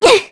Aselica-Vox_Damage_kr_03.wav